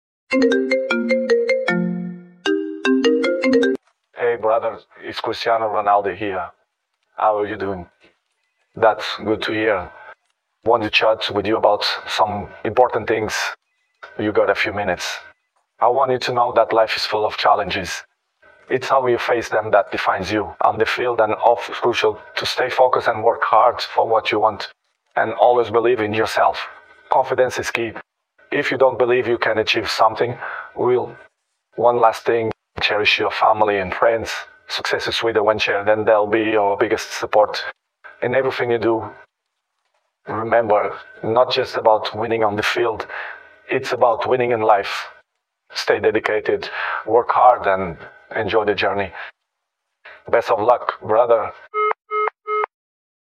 📞 FAKE CALL with CRISTIANO RONALDO?! 🤯⚽ Imagine getting a call from CR7 himself—sharing football tips, motivation, and legendary advice!